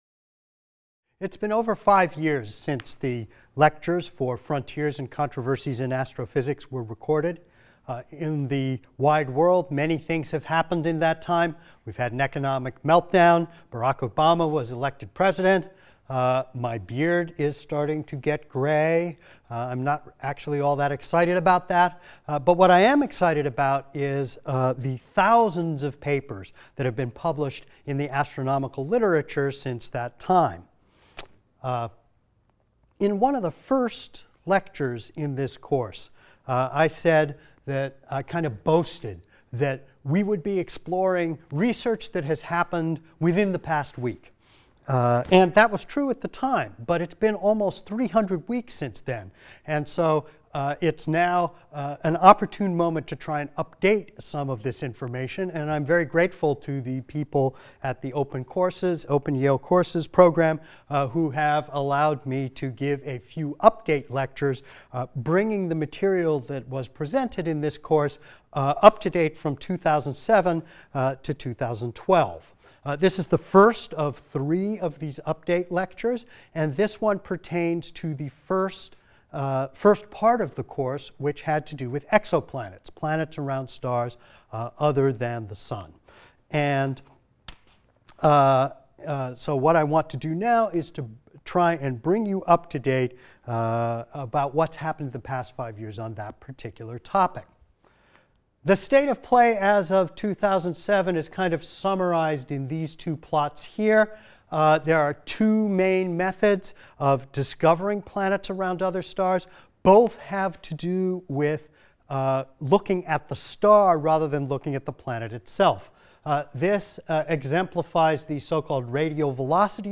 ASTR 160 - Update 1 - The Kepler Mission | Open Yale Courses